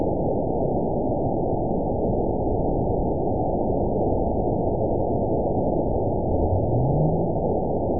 event 920318 date 03/15/24 time 19:07:14 GMT (1 year, 1 month ago) score 9.56 location TSS-AB05 detected by nrw target species NRW annotations +NRW Spectrogram: Frequency (kHz) vs. Time (s) audio not available .wav